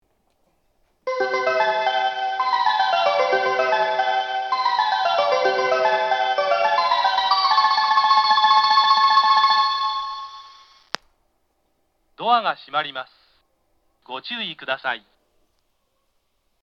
当駅は放送装置の調子が悪いのか、接近チャイムや発車メロディーを切った時ににノイズが入ります。
放送更新前の音声
発車メロディー
フルコーラスです。